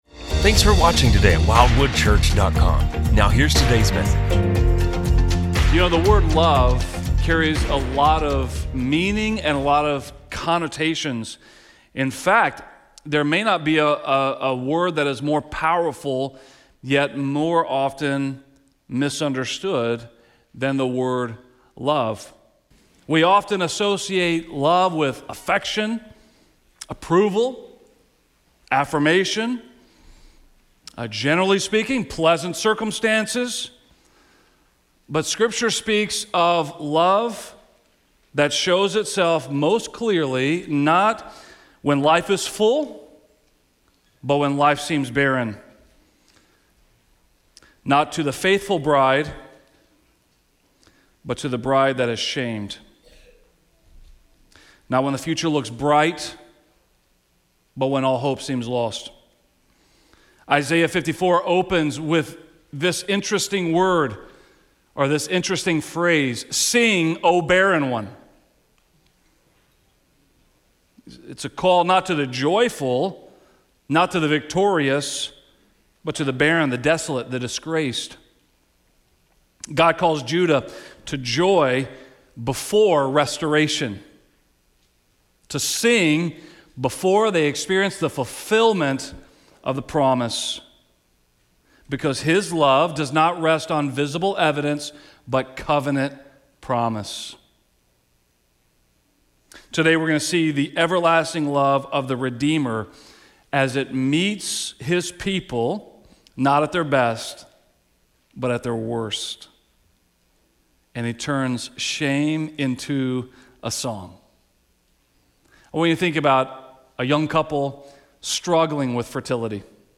In Isaiah 54, the Lord commands the desolate to sing before restoration comes, grounding hope not in circumstances but in covenant promise. This sermon traces God’s everlasting love from exile to Bethlehem, showing how divine wrath and mercy meet in Christ.